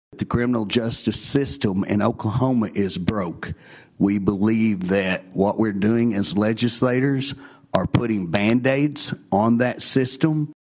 CLICK HERE to listen to the commentary from Justin Humphrey.